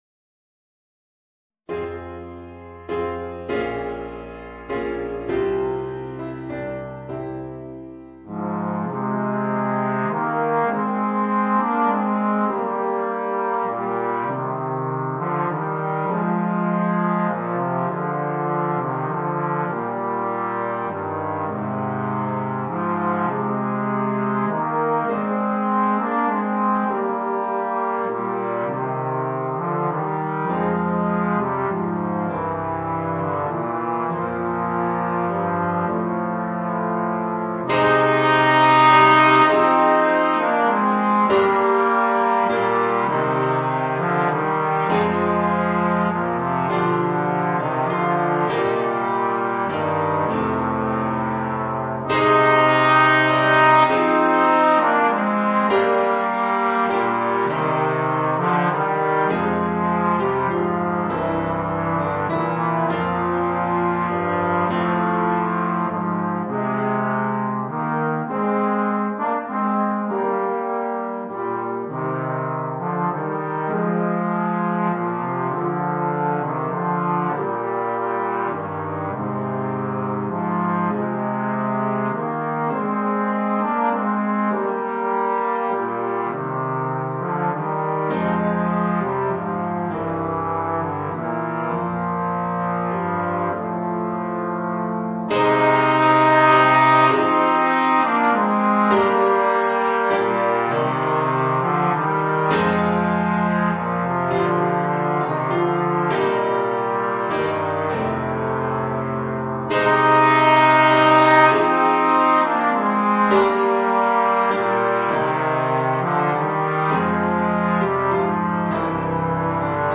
2 Trombones